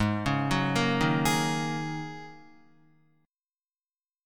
Ab7sus2sus4 chord